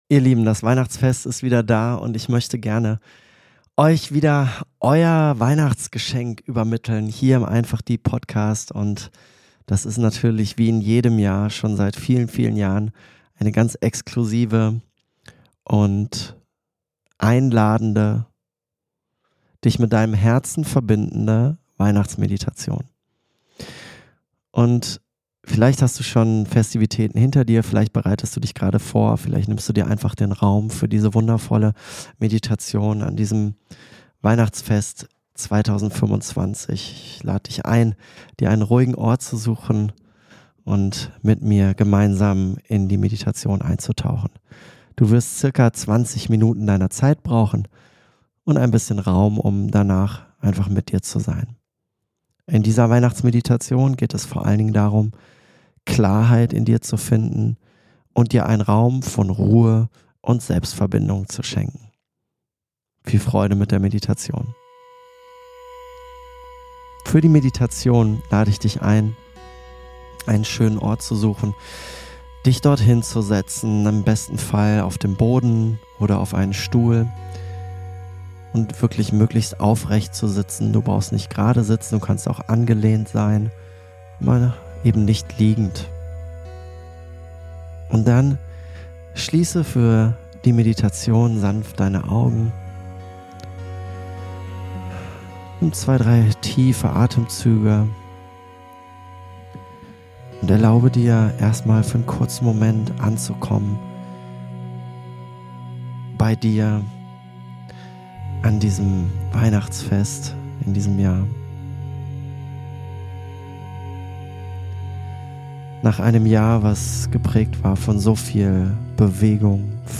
Es geht darum, Dich mit Deinem Herzen zu verbinden, in Selbstfürsorge und Selbstannahme zu gehen und im inneren Frieden anzukommen. In dieser Meditation erfährst Du: – Wie Du den Stress der Feiertage loslässt – Wie Du Deine Atmung nutzt, um Dich zu entspannen – Warum es wichtig ist, in den Moment zu kommen – Wie Du Deine Liebe zu Dir selbst wiederentdeckst – Warum Weihnachten der perfekte Moment ist, ganz bei Dir zu sein Nutze diese Meditation für Dich – für innere Ruhe, Klarheit und eine tiefe Verbindung zu Dir selbst.